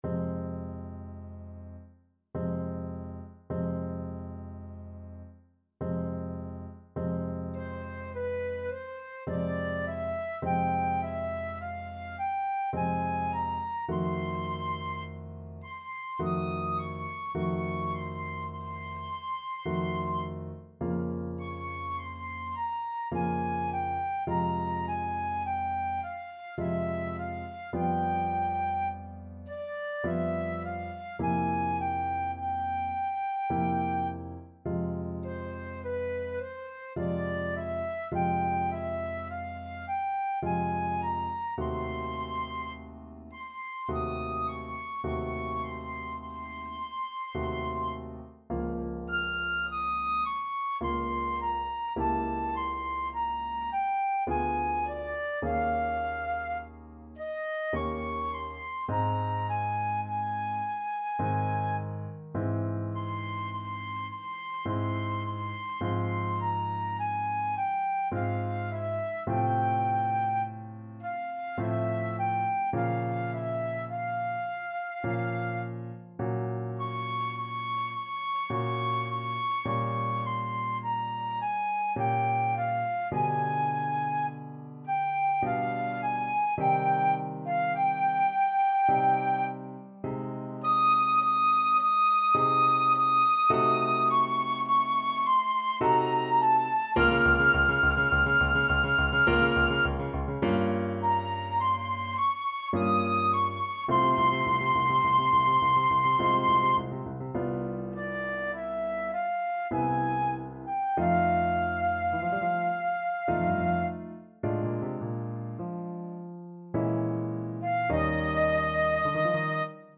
Flute version
Time Signature: 3/4
Tempo Marking: Andante molto moderato
Score Key: F minor (Sounding Pitch)